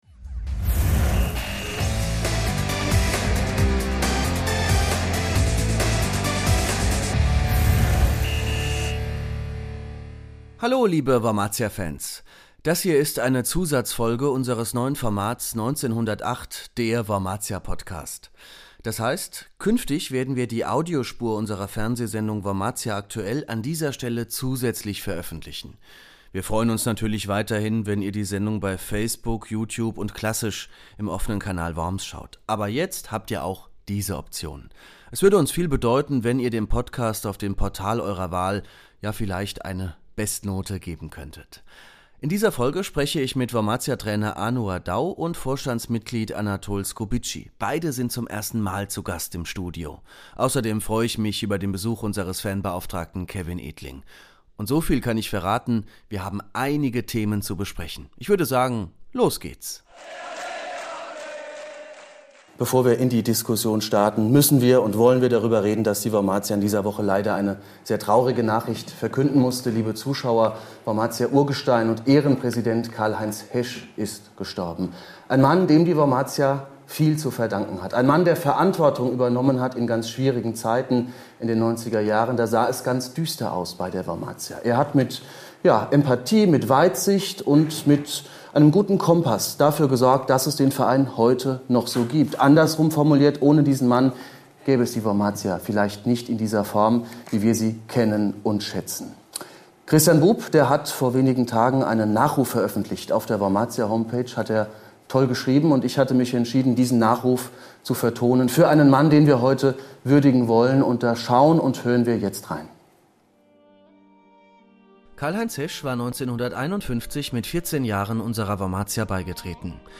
Beschreibung vor 5 Monaten Künftig werden die Ausgaben der Fernsehsendung "Wormatia aktuell" auch hier als Podcast erscheinen.